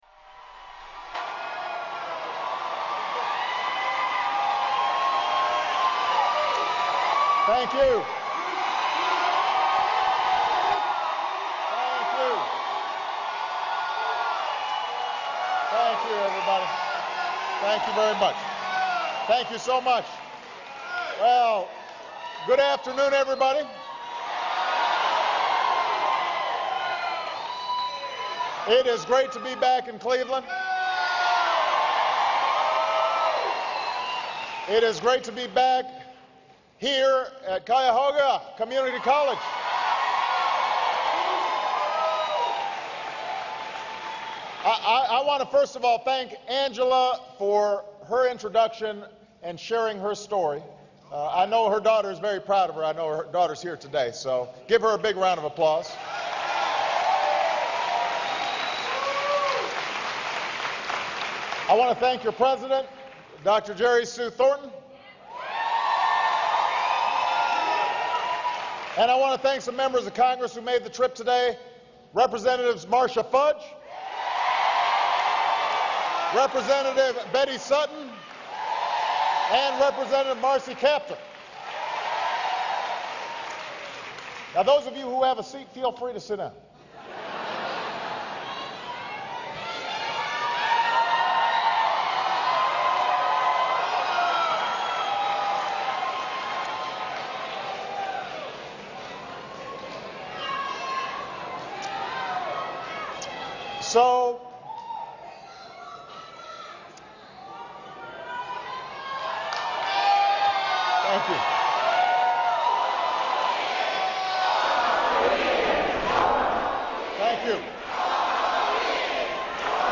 U.S. President Barack Obama talks about jobs and the economy during a campaign speech at Cuyahoga Community College in Ohio